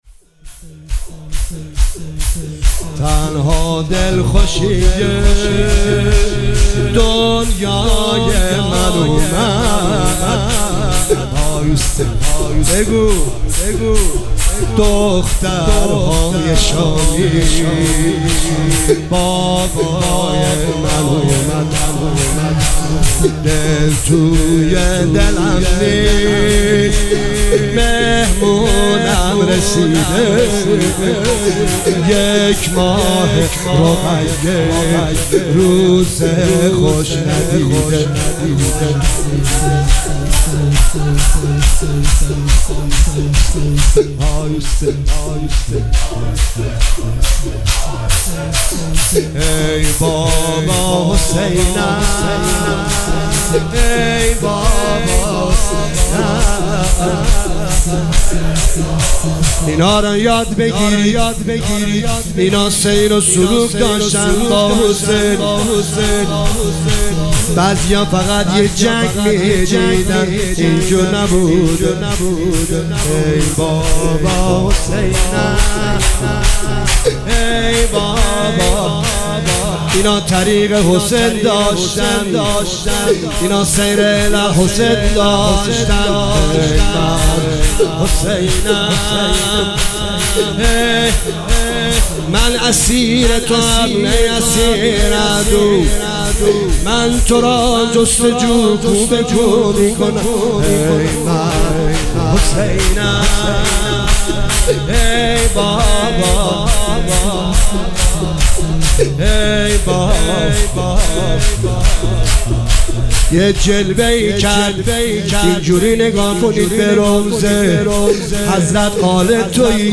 مداحی شور
شب سوم محرم
روز سوم محرم 1404